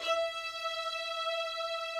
Updated string samples
strings_064.wav